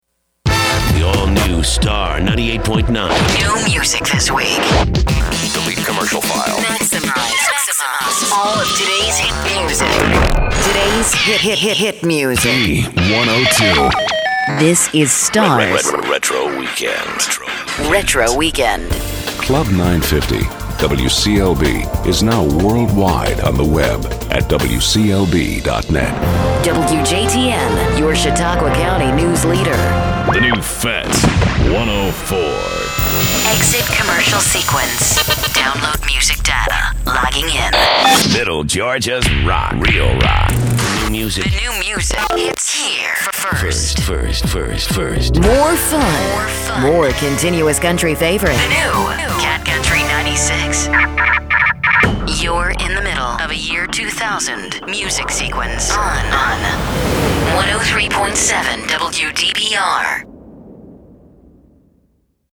Radio Imaging Demo
imagingdemo.mp3